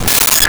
Glass Clink Single
Glass Clink Single.wav